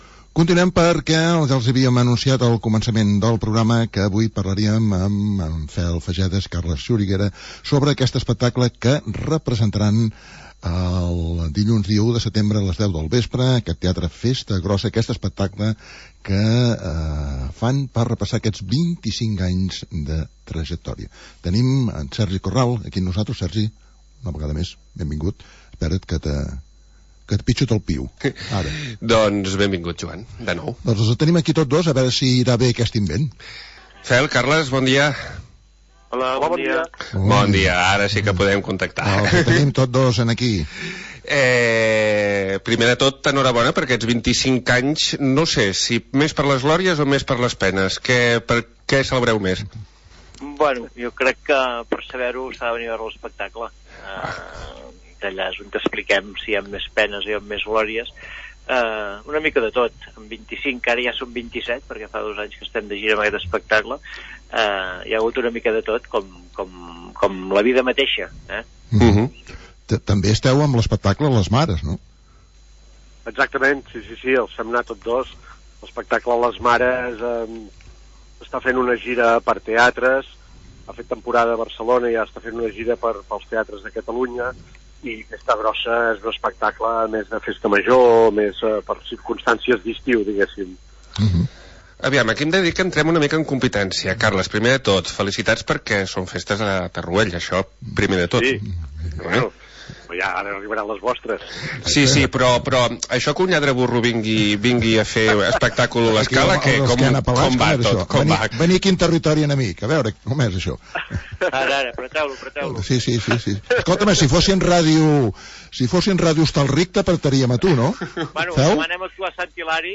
Entrevista amb en Fel Faixedas i Carles Xuriguera